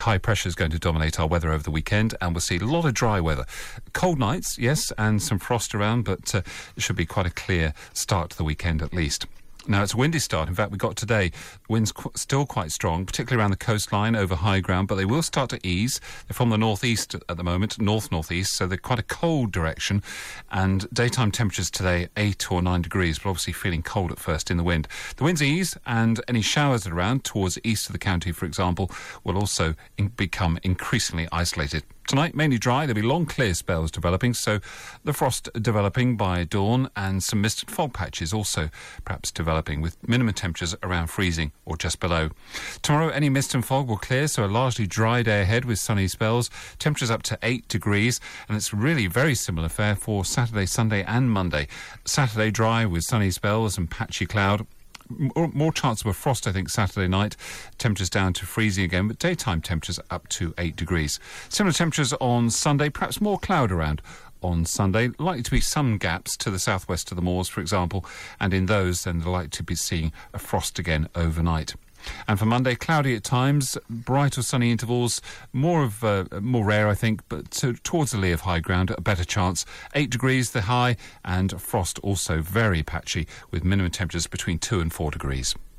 5 day forecast for Devon from 8.35AM on 21 November